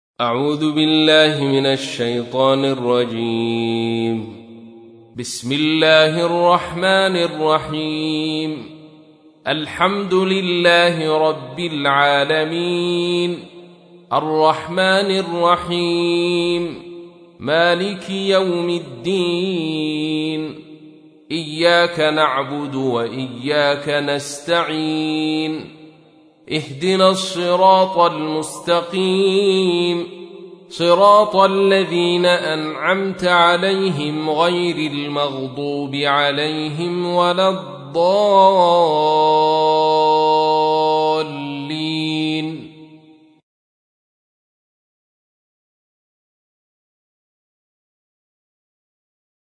تحميل : 1. سورة الفاتحة / القارئ عبد الرشيد صوفي / القرآن الكريم / موقع يا حسين